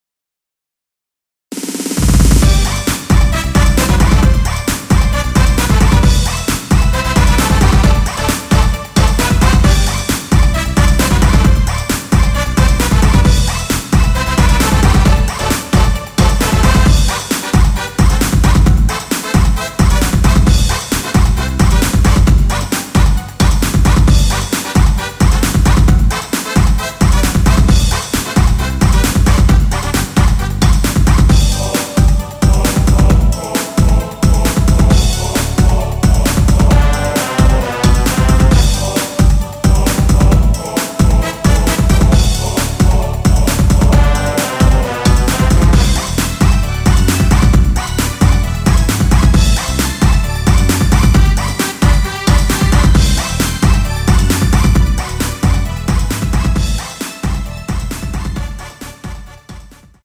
전주가 길어서 원곡의 20초 부터 시작하게 제작하였습니다.
◈ 곡명 옆 (-1)은 반음 내림, (+1)은 반음 올림 입니다.
앞부분30초, 뒷부분30초씩 편집해서 올려 드리고 있습니다.
중간에 음이 끈어지고 다시 나오는 이유는